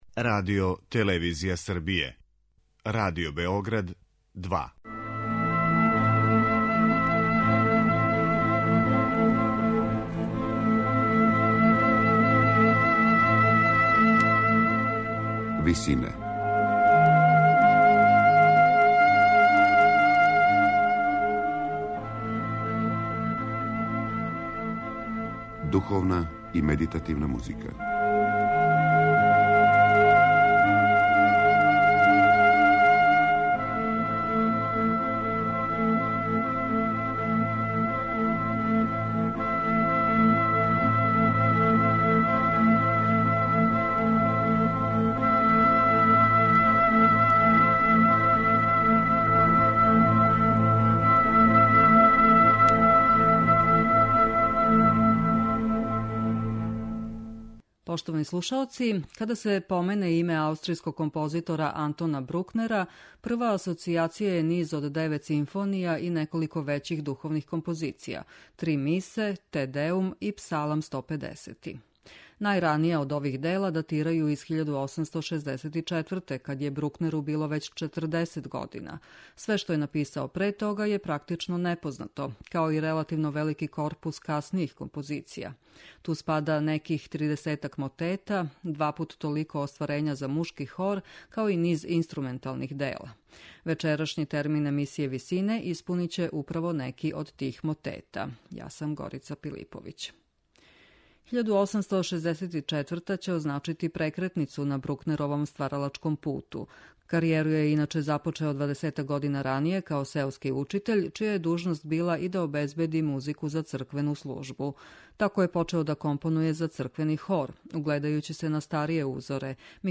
Мотети Антона Брукнера